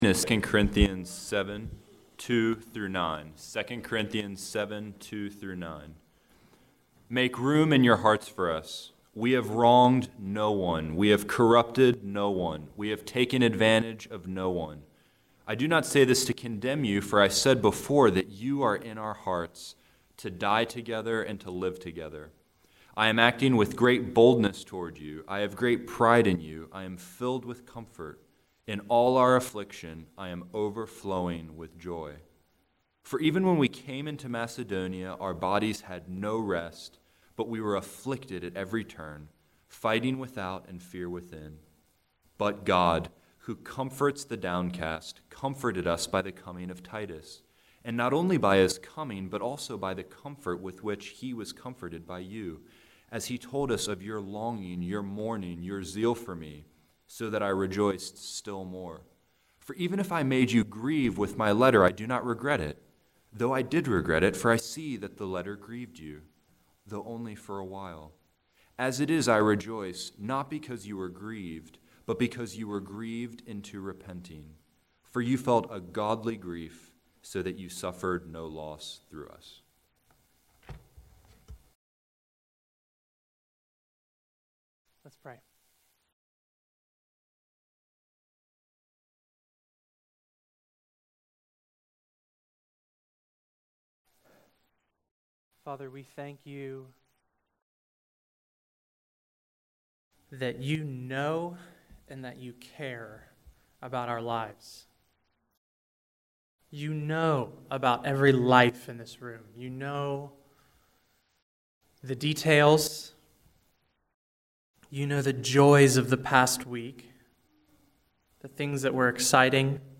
April 9, 2017 Morning Worship | Vine Street Baptist Church
The sermon continues the verse by verse series through 2 Corinthians.